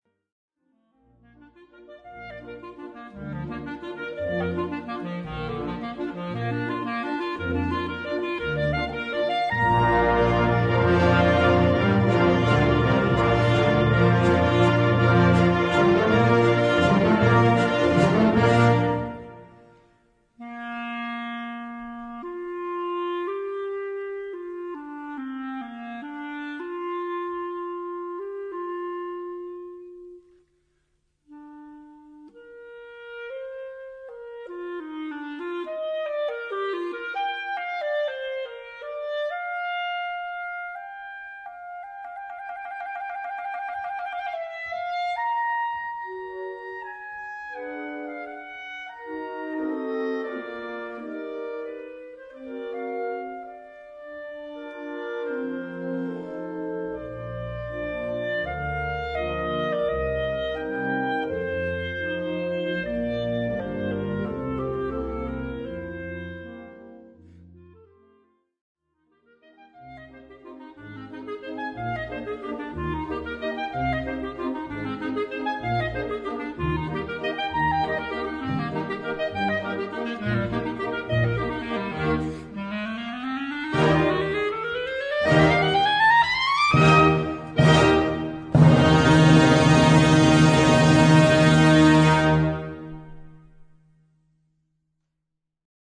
Gattung: Solo für Klarinette und Blasorchester
Besetzung: Blasorchester